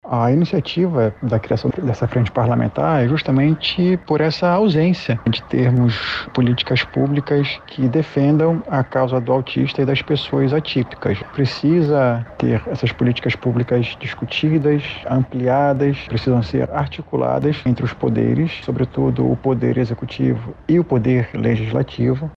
O vereador explica que a iniciativa da Frente Parlamentar foi pensada em virtude da falta de representatividade desse grupo nas casas legislativas do país.
SONORA-1-FRENTE-PARLAMENTAR-AUTISTAS-.mp3